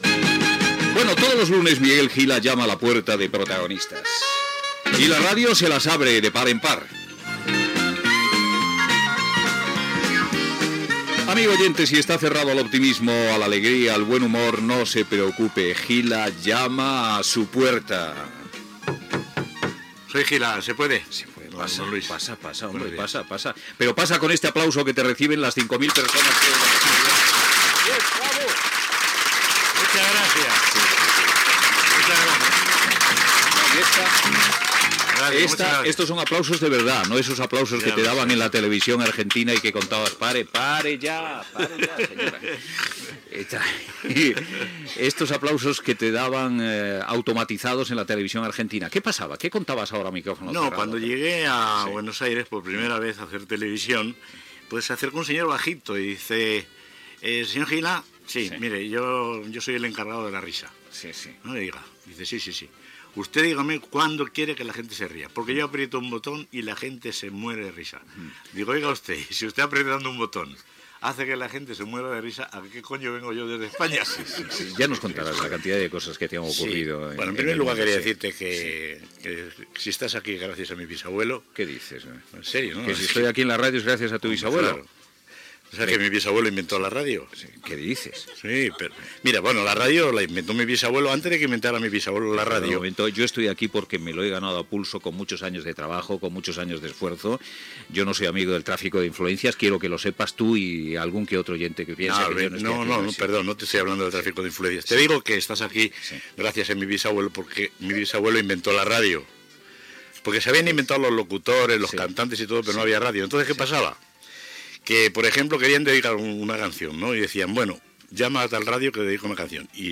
Fragment de la secció humorística "Gila llama a su puerta".
Info-entreteniment
FM